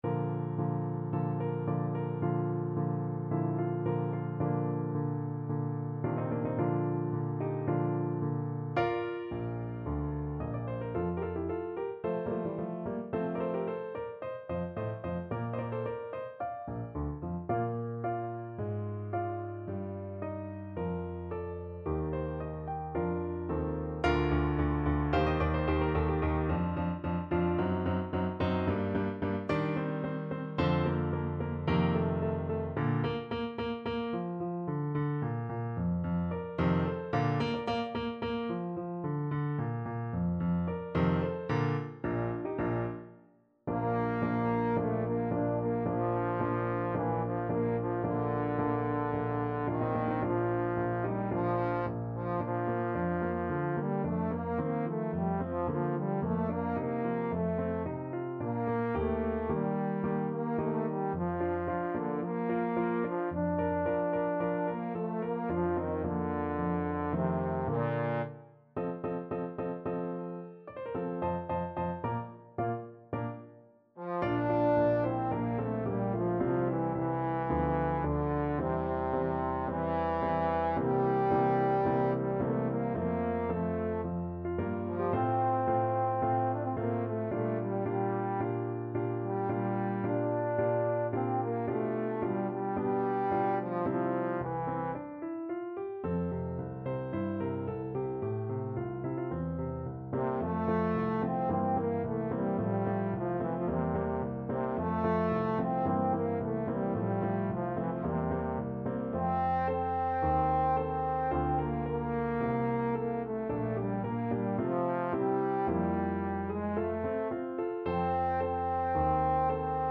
Classical Mozart, Wolfgang Amadeus Ich baue ganz auf deine Starke from The Abduction from the Seraglio Trombone version
Trombone
Bb major (Sounding Pitch) (View more Bb major Music for Trombone )
~ = 110 Andante
4/4 (View more 4/4 Music)
Classical (View more Classical Trombone Music)